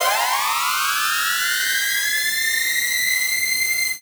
CDK Transition 2.wav